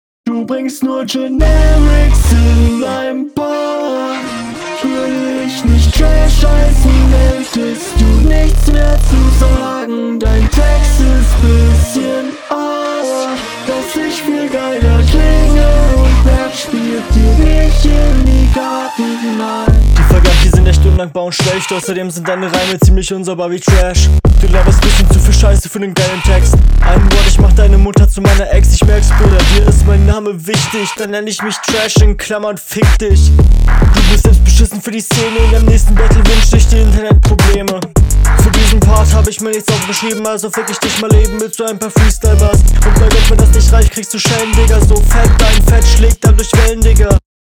Der Beat hat was, aber harmoniert irgendwie nicht so mit dir finde ich.
Interessanter Beatpick.